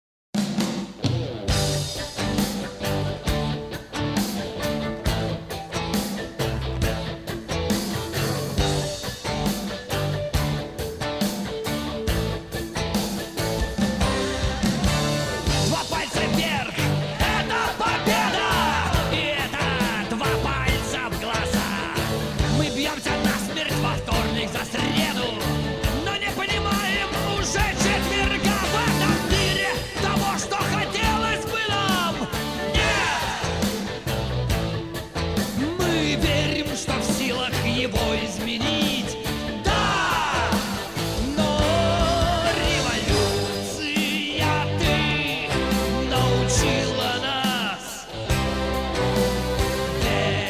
Рок
бескомпромиссные, жёсткие, ироничные, динамичные